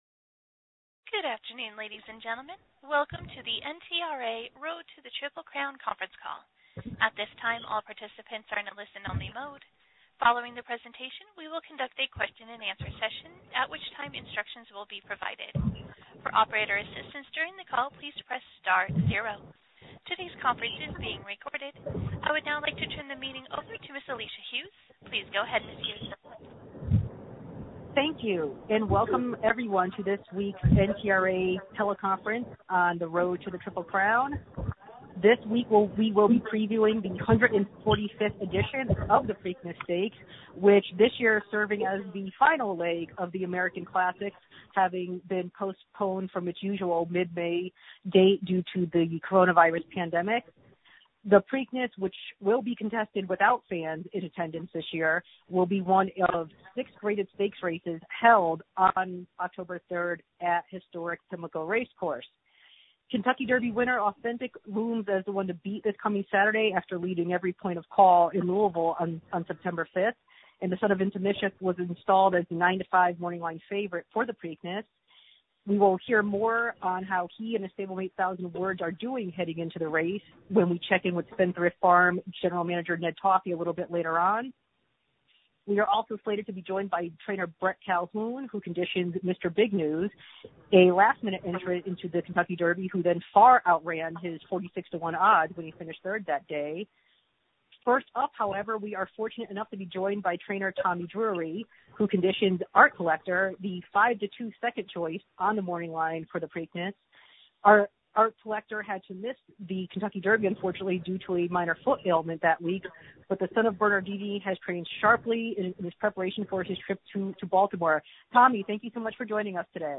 National Media Teleconference